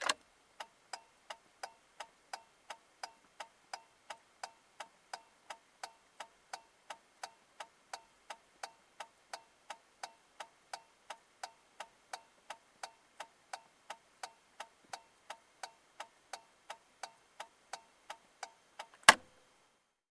Звуки поворотников
На этой странице собраны различные звуки поворотников автомобилей: от классических щелчков реле до современных электронных сигналов.